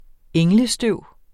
Udtale [ ˈεŋlə- ]